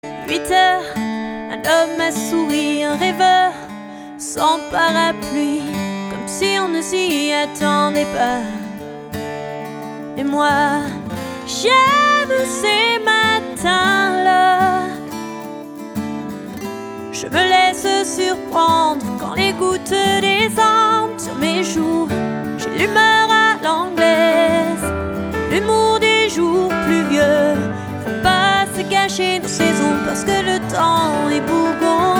Un bon petit disque de pop rock française.
La voix ne tremble pas.